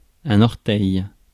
Ääntäminen
Ääntäminen : IPA : /ˈdɪdʒ.ɪt/ US : IPA : [ˈdɪdʒ.ɪt]